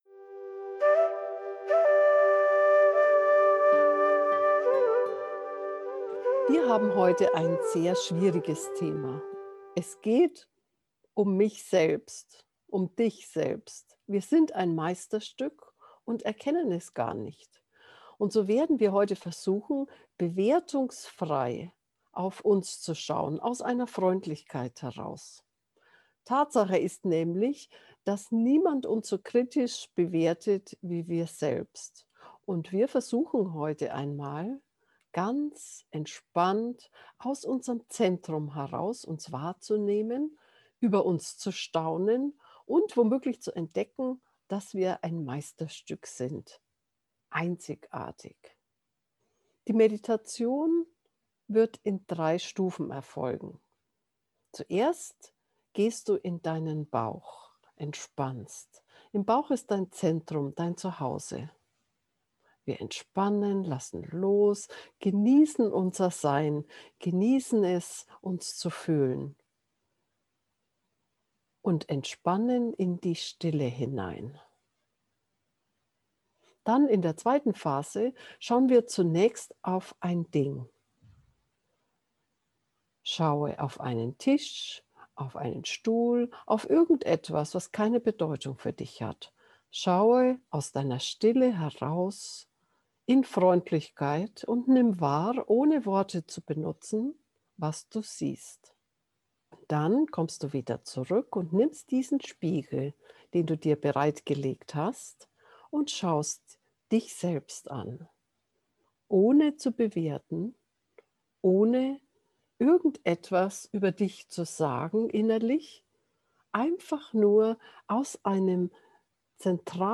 Geführte Meditationen Folge 108: Du bist ein Meisterstück!